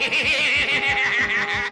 Skeletor hehehehahahahaha